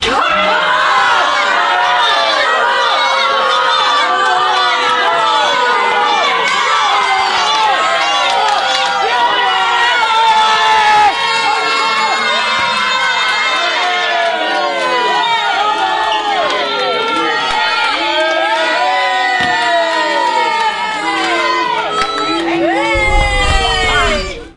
足球场上的球迷对裁判不满意
描述：足球比赛（足球在大西洋的另一边），人群众多。球迷对裁判不满意。体育场是Aris F.C.在希腊更具体。
标签： 拍手 小姐 足球 唱歌 嘘声 球场 比赛 裁判 球迷 喊着 欢呼声 比赛 足球 人群 体育
声道立体声